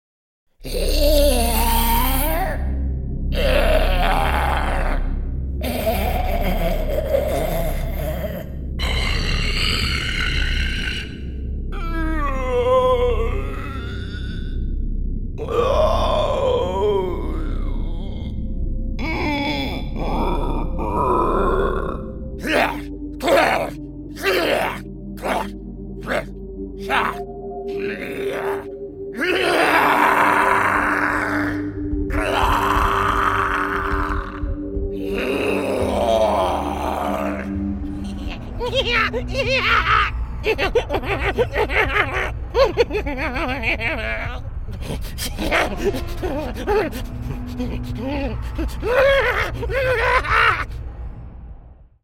All recordings are done in my professional sound-treated vocal booth.